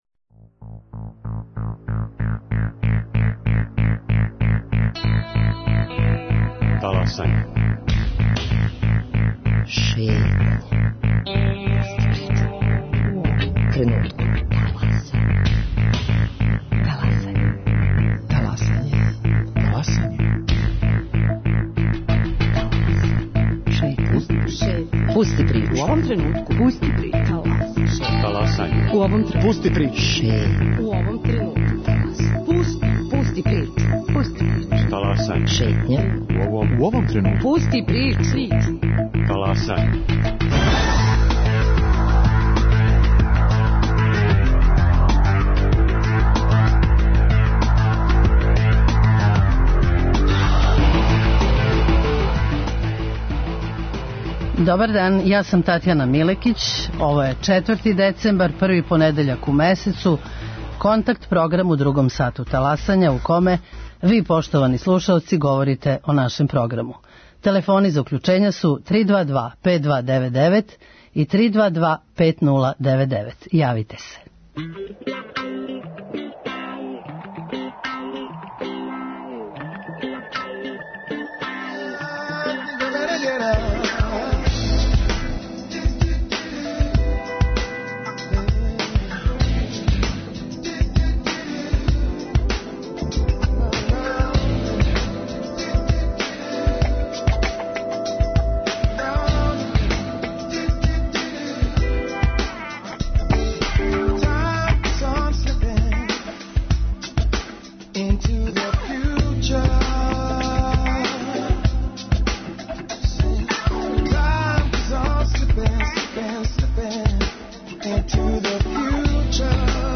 Као и сваког првог понедељка у месецу, и данас вам пружамо прилику да 'таласате' заједно са нама. Ви причате, сугеришете, коментаришете - ми слушамо!